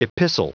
Prononciation du mot epistle en anglais (fichier audio)
Prononciation du mot : epistle